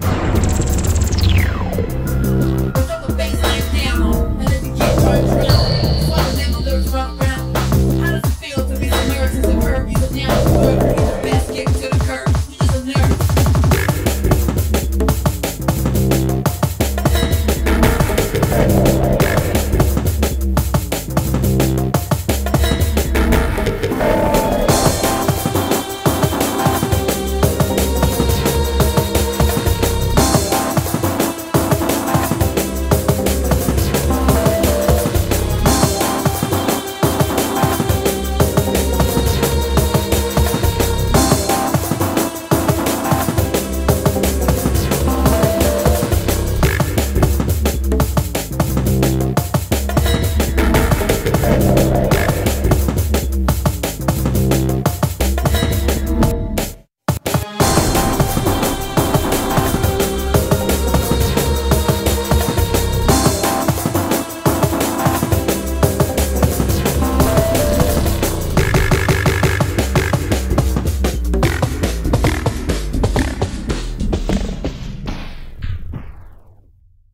BPM95-175